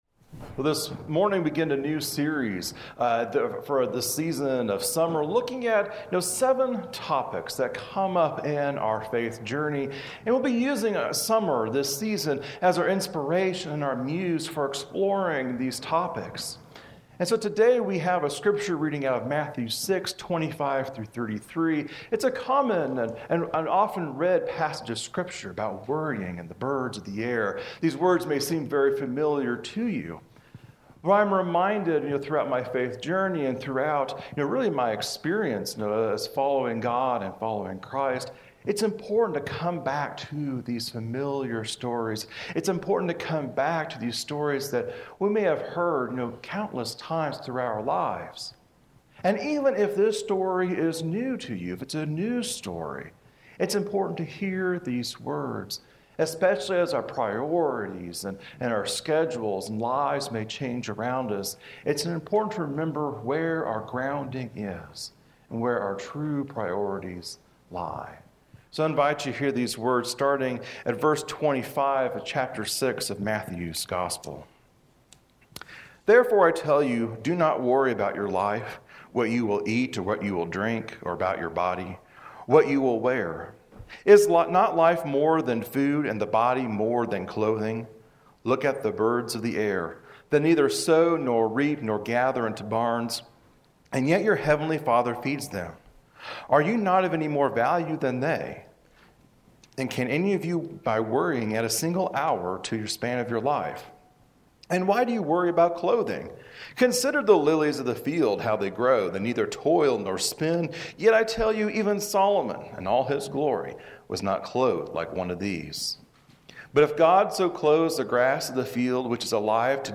Sunday-Sermon-Audio-June-23.mp3